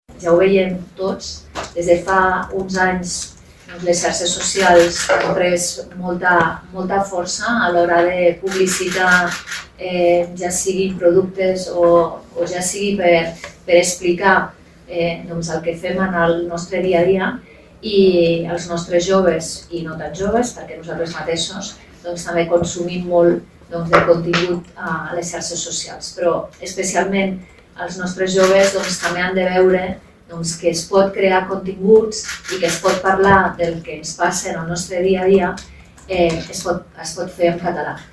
L’objectiu és demostrar que es pot triomfar a les xarxes socials creant continguts en català. La ministra de Cultura, Joventut i Esports, Mònica Bonell, ha destacat que “si volem preservar la nostra llengua és imprescindible assegurar que s’utilitzi en tot l’àmbit digital”.